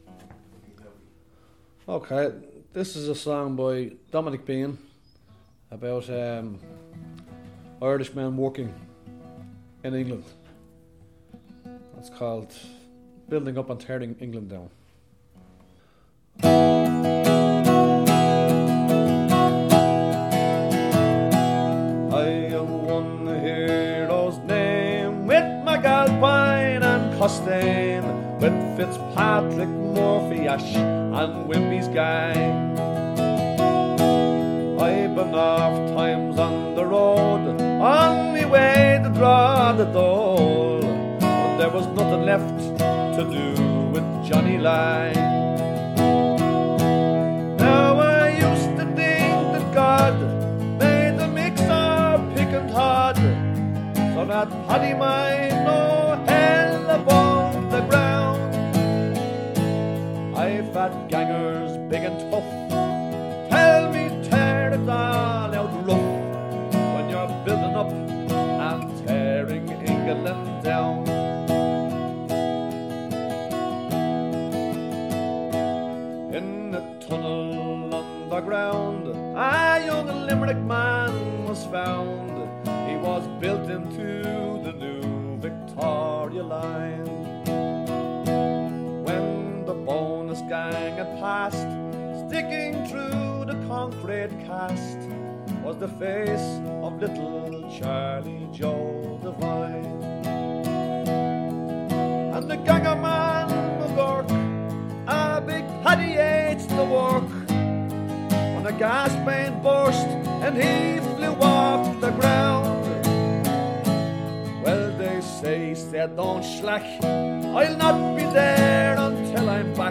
cover version